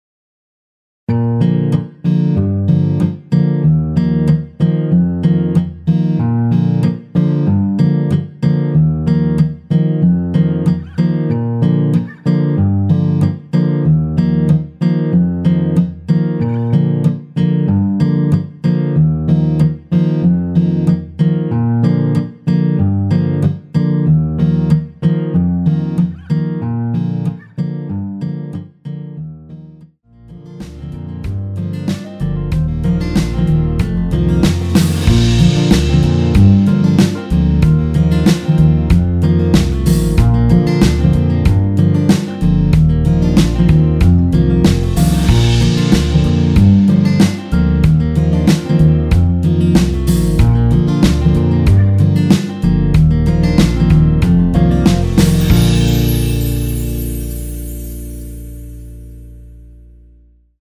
원키에서(-1)내린 MR입니다.
Bb
앞부분30초, 뒷부분30초씩 편집해서 올려 드리고 있습니다.